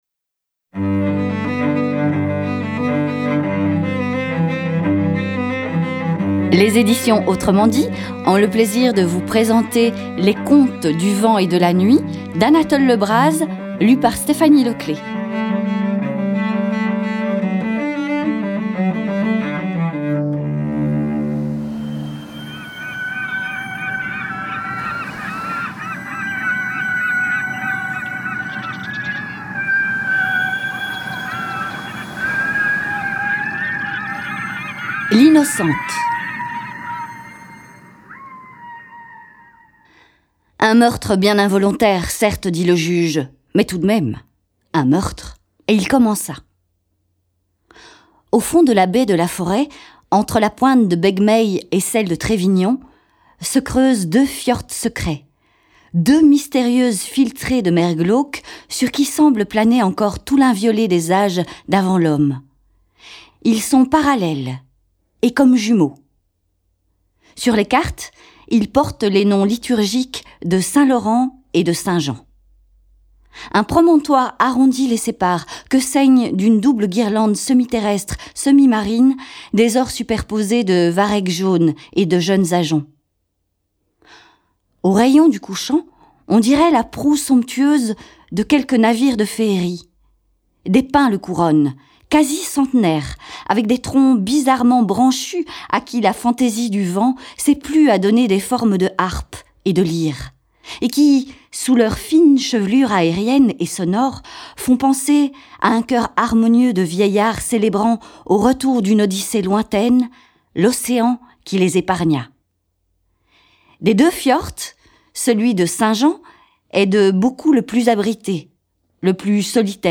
Lecture intégrale de contes bretons